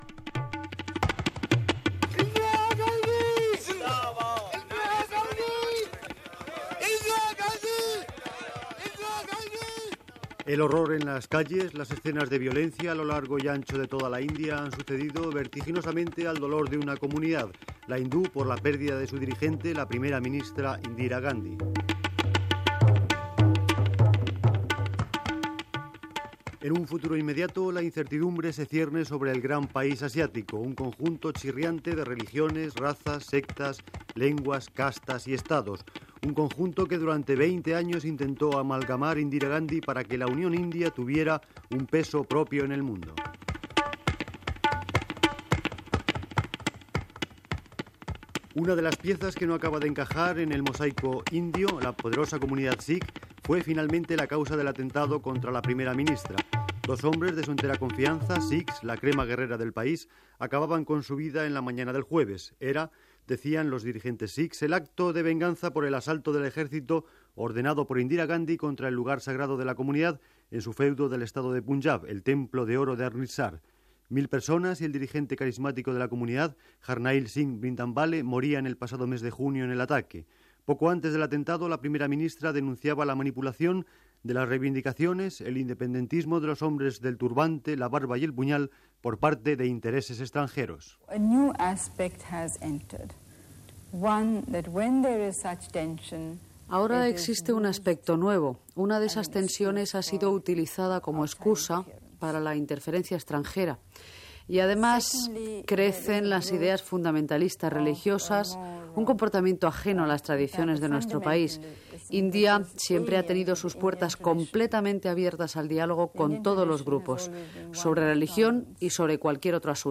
Reportatge sobre l'assassinat dela primera ministra de l'Índia Indira Gandhi, el 31 d'octubre de 1984
Informatiu